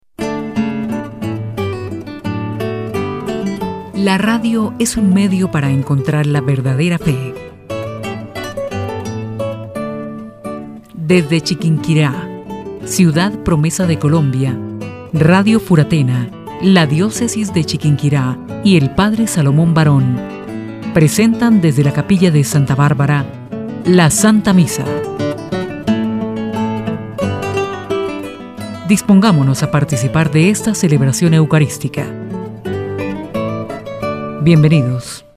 locutora, voz femenina, voz informativa, noticias, seria, grave, animados, institucional
Sprechprobe: Sonstiges (Muttersprache):